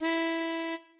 melodica_e.ogg